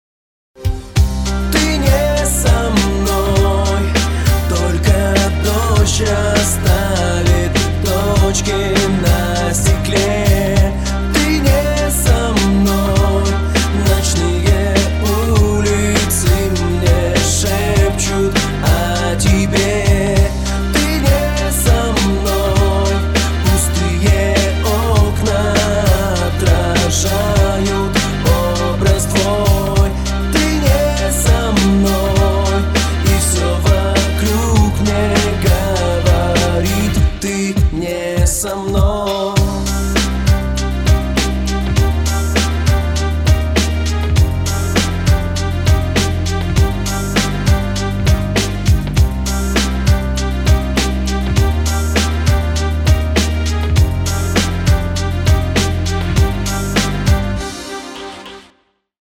закольцованный два раза.
(Качество - 44,1kHz, 320kbps, Stereo, 01:00) Ура!